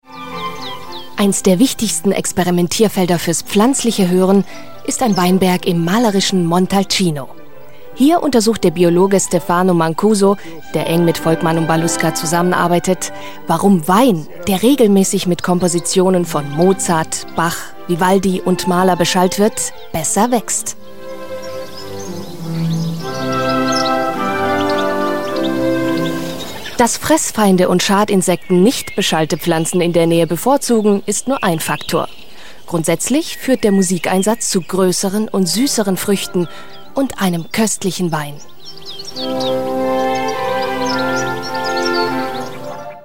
deutsche, junge Sprecherin. Ihre Stimme klingt lebendig, freundlich, jung, frisch und klar.
Sprechprobe: eLearning (Muttersprache):
german female voice over artist, young voice